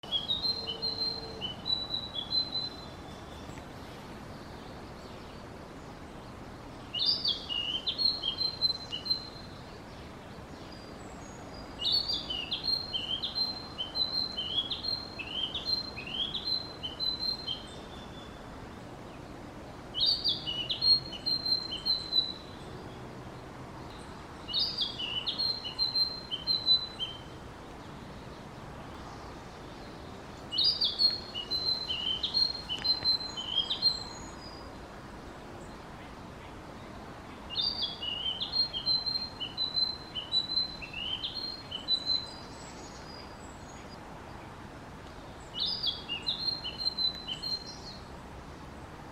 キビタキ（声）
曇天の中、キビタキがよくさえずっていた。